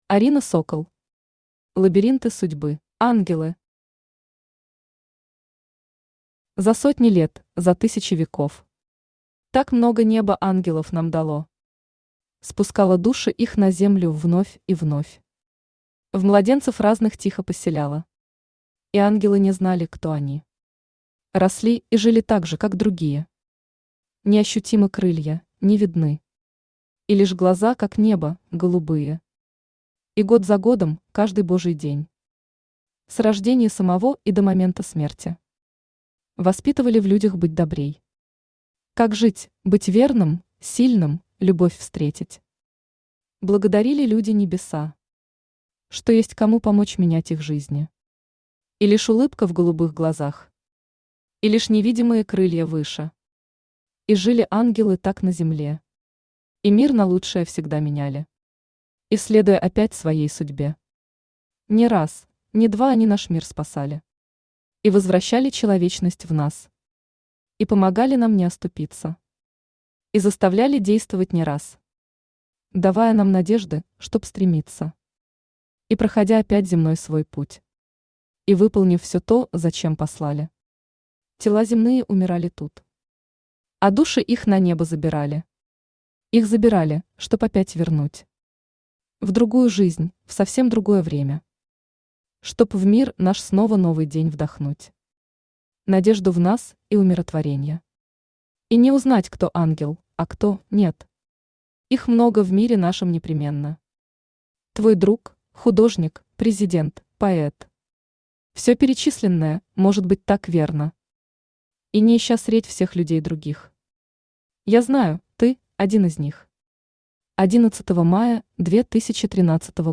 Аудиокнига Лабиринты судьбы | Библиотека аудиокниг
Aудиокнига Лабиринты судьбы Автор Арина Сокол Читает аудиокнигу Авточтец ЛитРес.